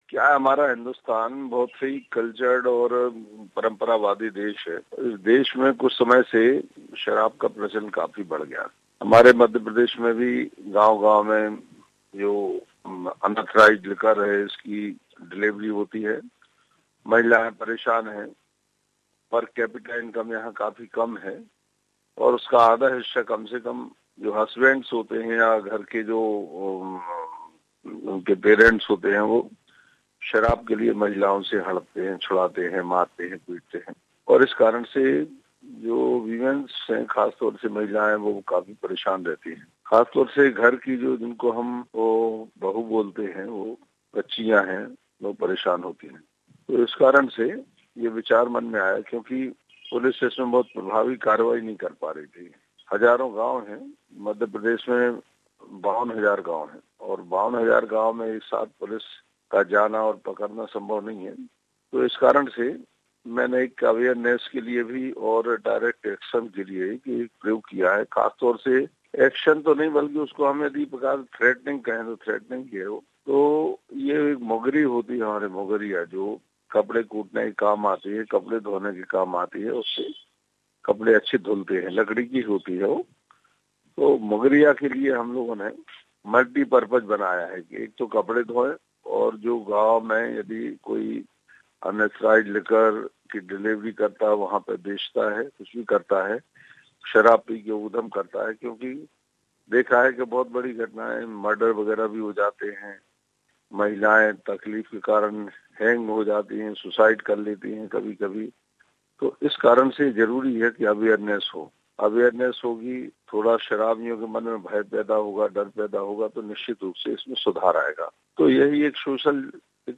EXCLUSIVE: The Indian state government minister, Gopal Bhargva, who gifted bats to brides to 'defend themselves against domestic violence' speaks to SBS Hindi Radio.